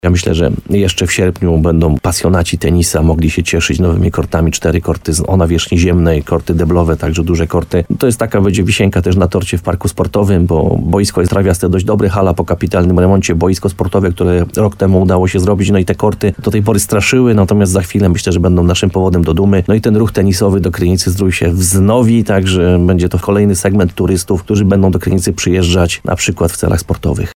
– Już niedługo będziecie mogli chwycić za rakiety i zagrać na nowej nawierzchni – mówi burmistrz, Piotr Ryba.